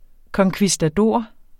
Udtale [ kʌŋkvisdaˈdoˀɐ̯ ]